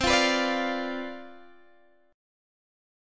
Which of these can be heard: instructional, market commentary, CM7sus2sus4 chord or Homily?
CM7sus2sus4 chord